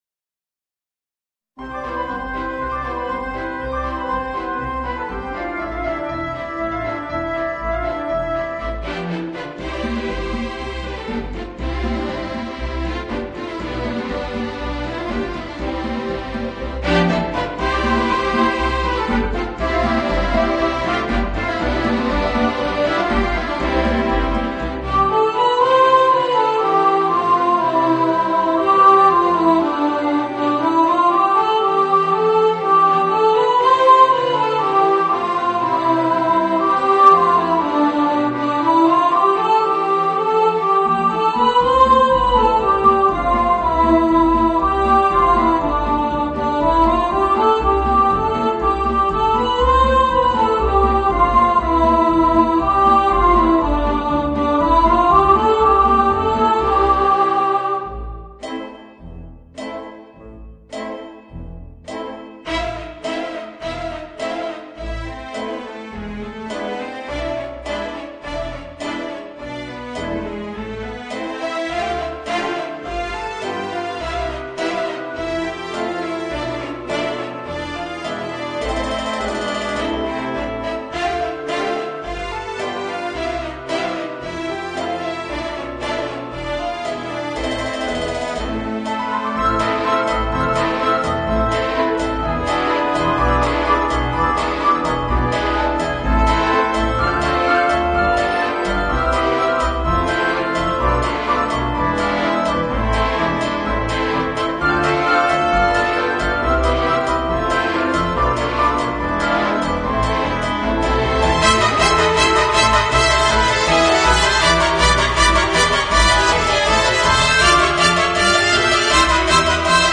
Voicing: Children's Choir and Orchestra